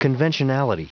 Prononciation du mot conventionality en anglais (fichier audio)
Prononciation du mot : conventionality